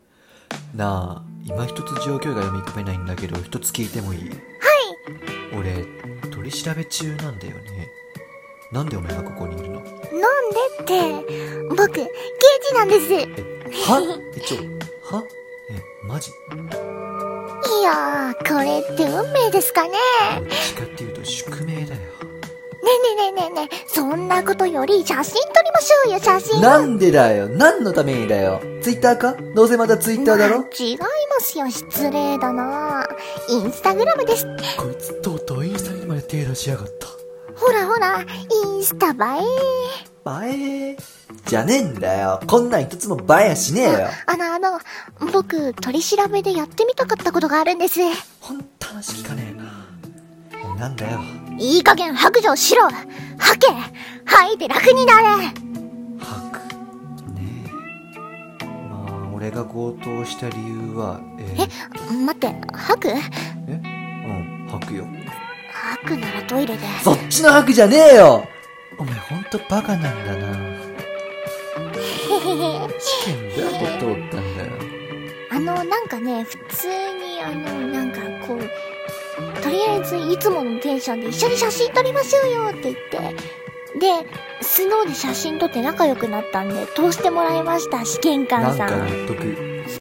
【ギャグ声劇】